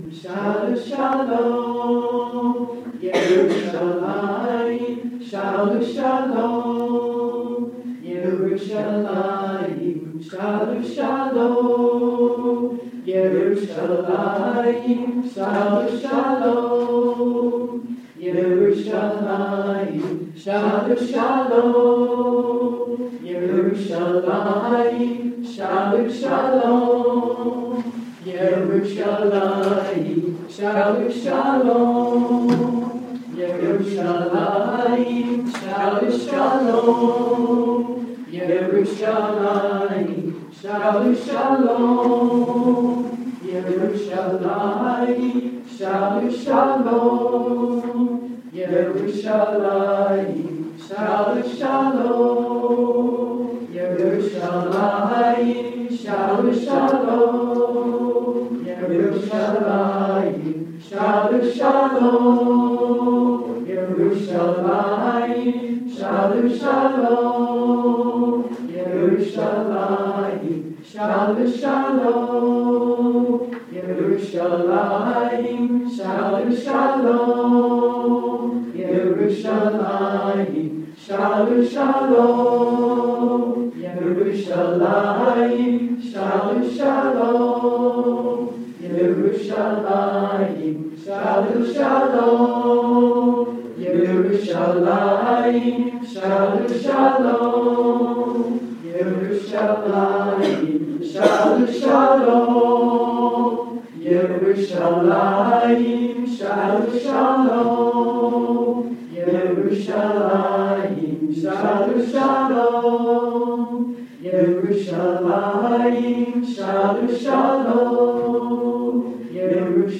Recorded at LIMMUD BAY AREA Sacred Chant Healing Circle
Sonoma State University, Rohnert Park, CA 2014